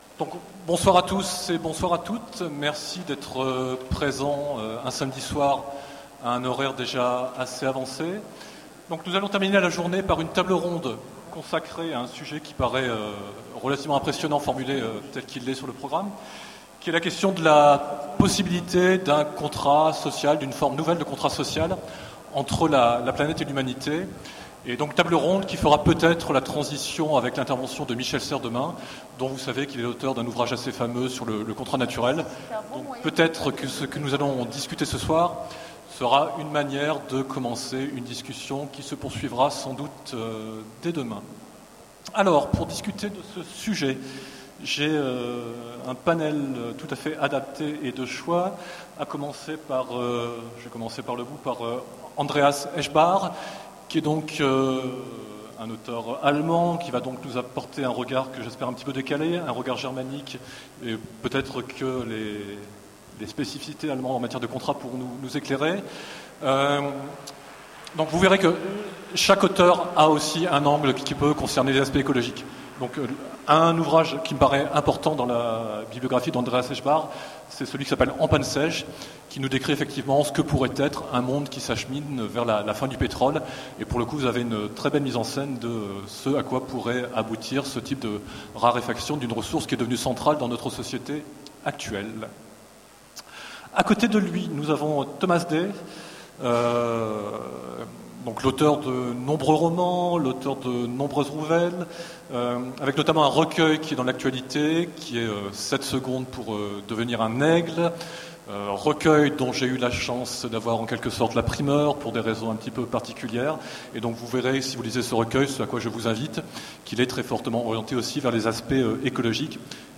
Utopiales 13 : Conférence Vers un nouveau contrat social entre la planète et l'humanité